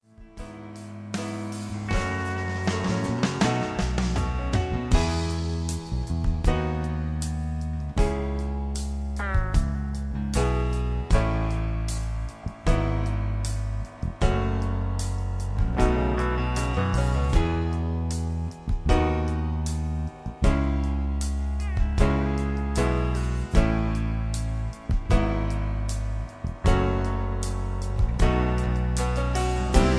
backing tracks
country rock, gospel